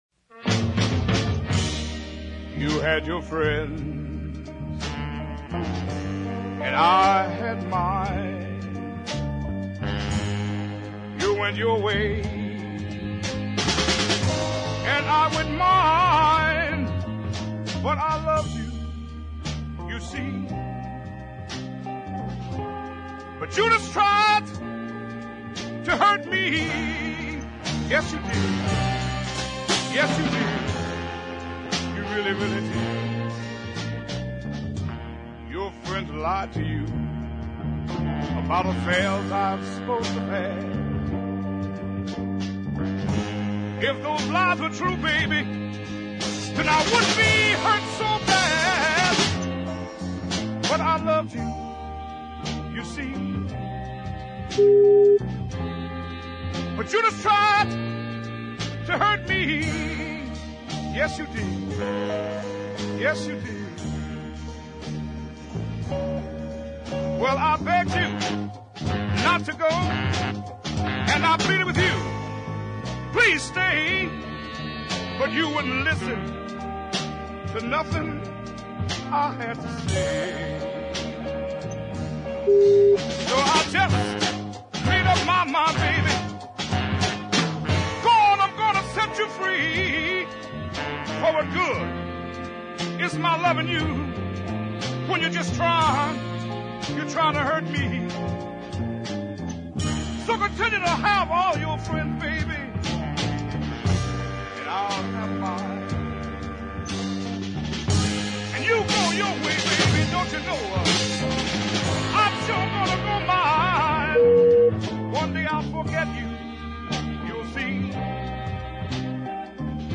Taken at a funereal pace